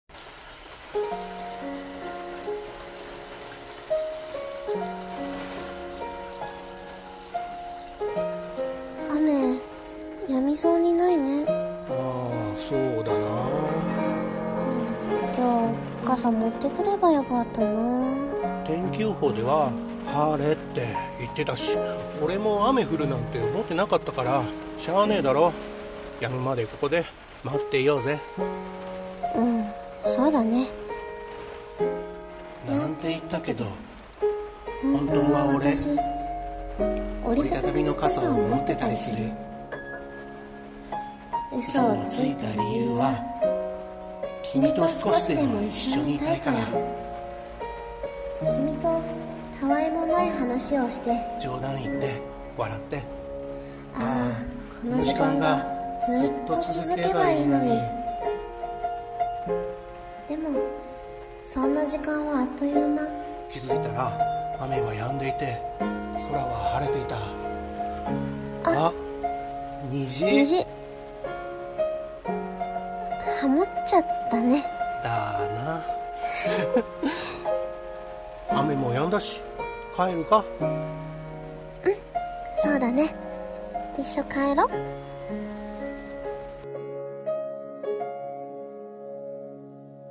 【声劇】君と少しでも一緒に【掛け合い】